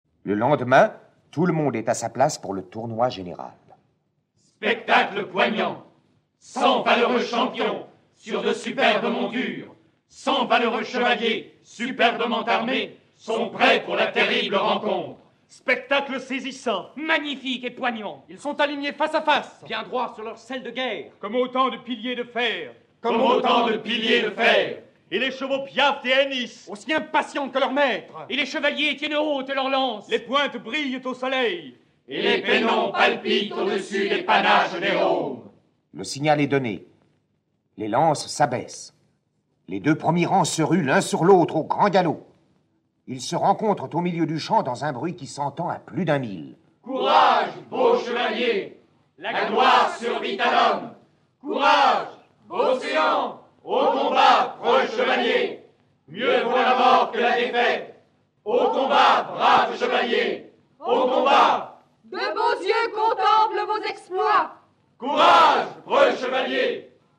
Click for an excerpt - Ivanhoe de Sir Walter Scott